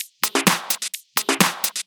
Index of /VEE/VEE Electro Loops 128 BPM
VEE Electro Loop 004.wav